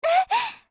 slayer_f_voc_hit_d.mp3